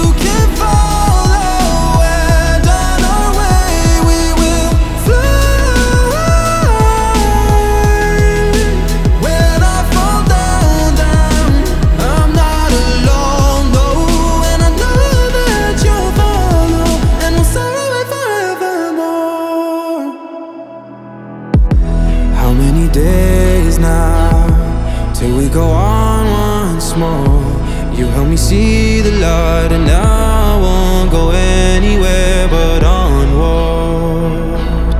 Жанр: Рок
# Pop, # Rock